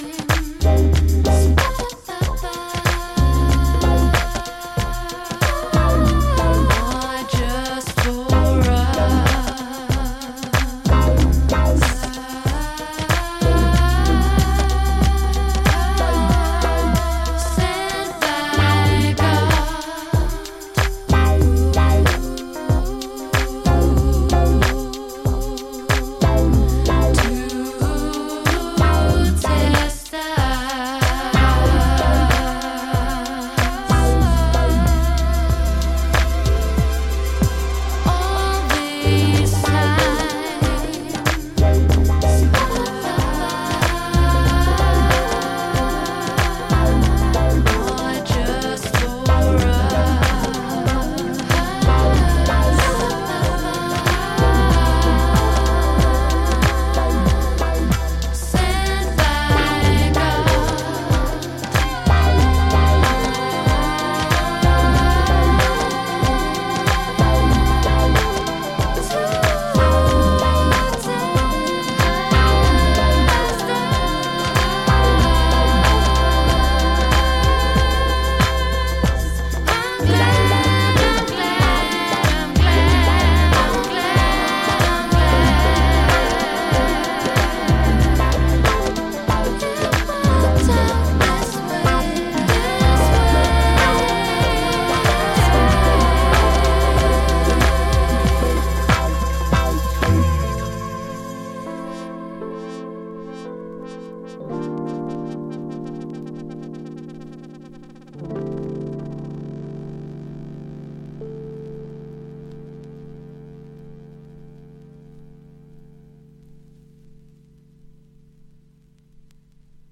ジャンル(スタイル) HOUSE / BROKEN BEAT / NU JAZZ / NU SOUL